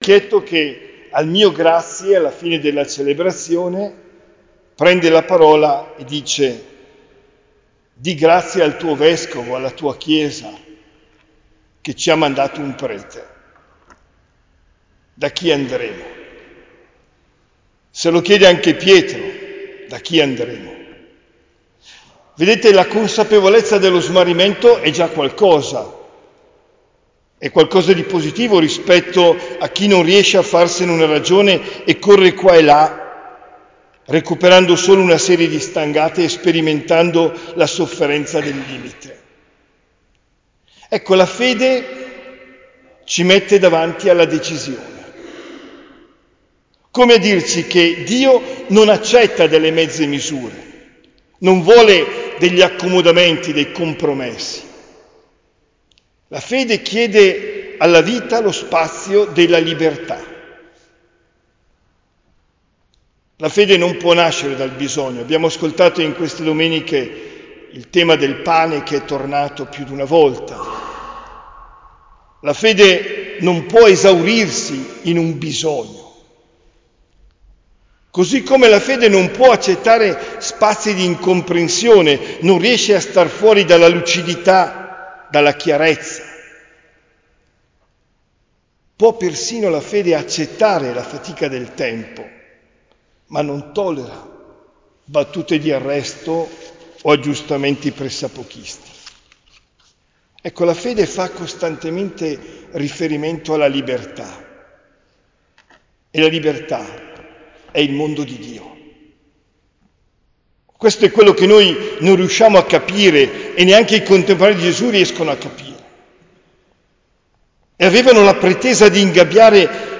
OMELIA DEL 25 AGOSTO 2024